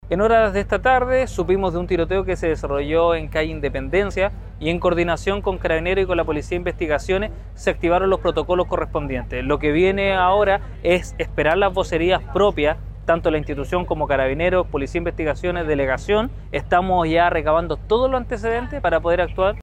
El alcalde de Limache, Luciano Valenzuela, señaló que como municipio están recabando los antecedentes del caso “para poder actuar”, mientras avanza la investigación policial.